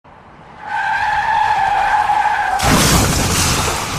Car Crash
# car # crash # impact About this sound Car Crash is a free sfx sound effect available for download in MP3 format.
275_car_crash.mp3